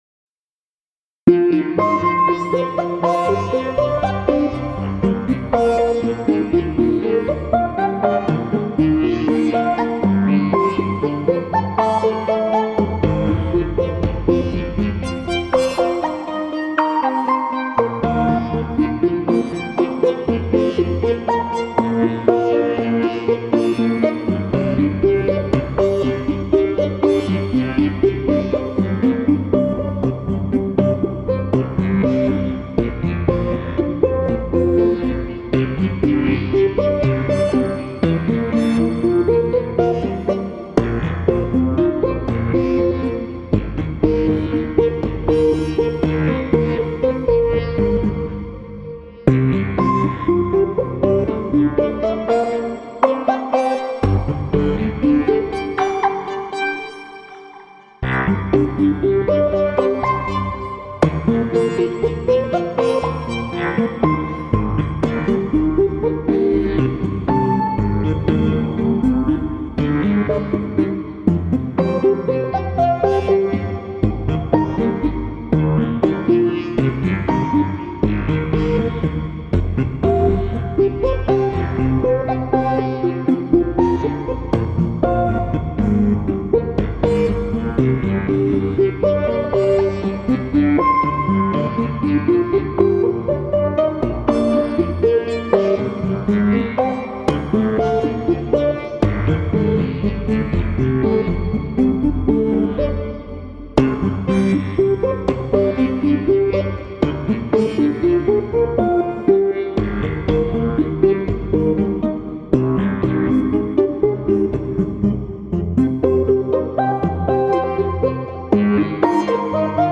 BGM
エレクトロニカロング穏やか